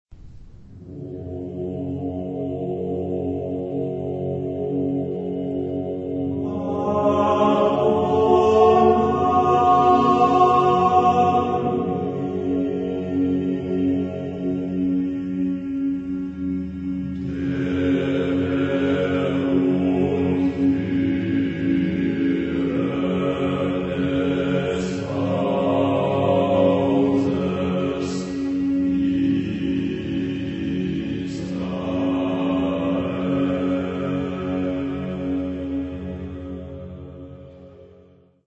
Genre-Style-Forme : Sacré ; Antienne ; Magnificat
Type de choeur : SSAATTBB  (8 voix mixtes )
Tonalité : bitonal ; modal